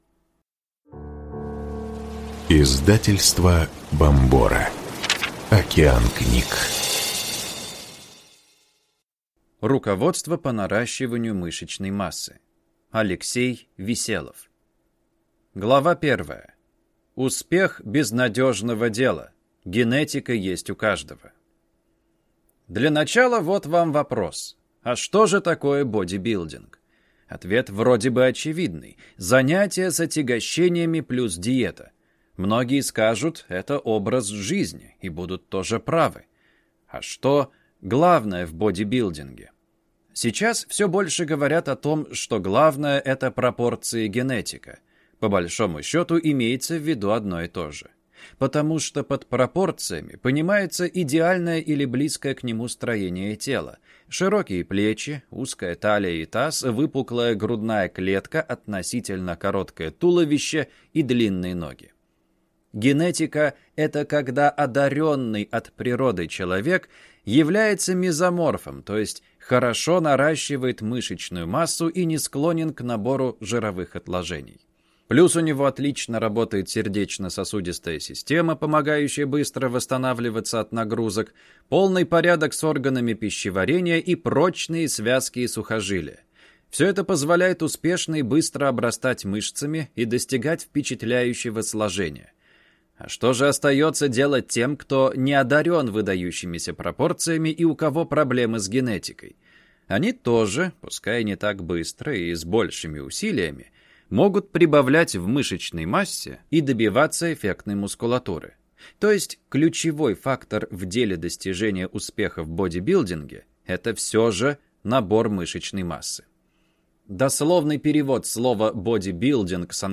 Аудиокнига Руководство по наращиванию мышечной массы | Библиотека аудиокниг